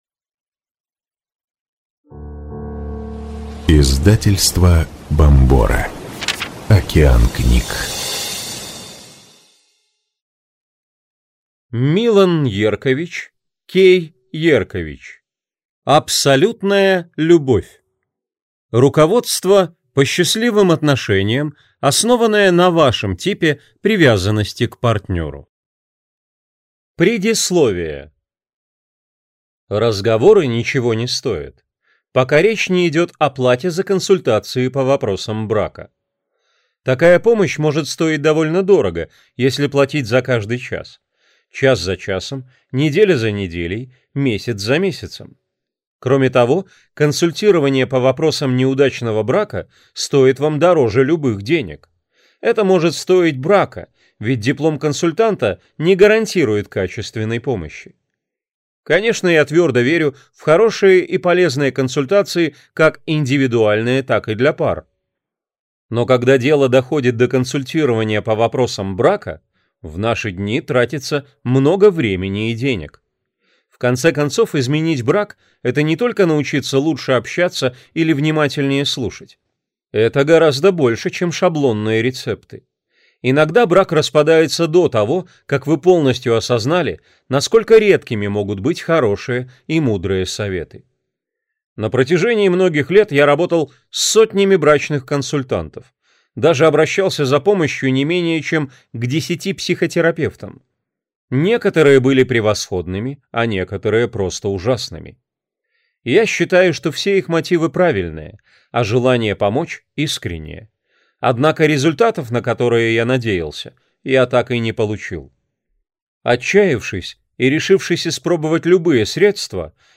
Аудиокнига Абсолютная любовь. Руководство по счастливым отношениям, основанное на вашем типе привязанности к партнеру | Библиотека аудиокниг